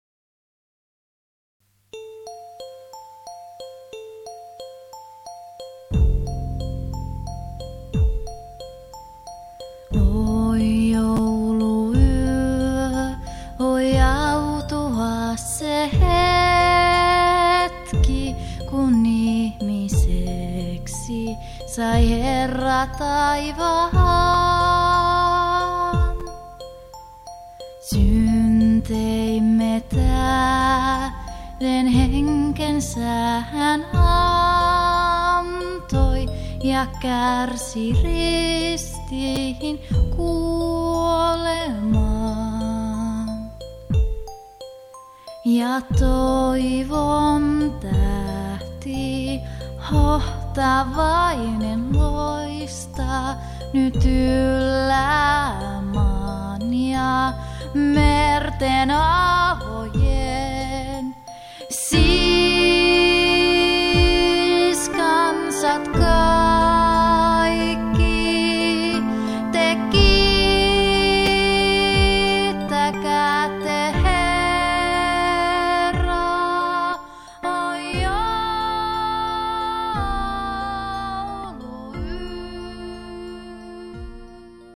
laulut
kitarat
Äänitys on tehty kotistudiossa Kemissä.
Muut instrumentit on ohjelmoitu MIDI-tekniikalla.